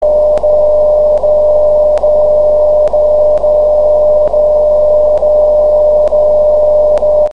Folgende Signale konnte ich auf meinem Amateurfunk-KW-Empfänger
in der Betriebsart CW (Morsen) als Ton empfangen (Stand 2006) :
-- 60.0Khz englische MSF-Signale        hier als
Bei den 100msec / 200msec Impulsen hört man keinen Ton.
Die Tonpausen sind also die Impulse.
Fast wie beim "Umgedrehten Morsen" ...
Bei allen Hörproben ist die Synchronisation-Lücken-Pause in der 59-zigsten Sekunde als längerer Ton gut zu erkennen.
Alle Hörproben (Codierungen) sind unterschiedlich, obwohl man dies nicht gleich hört.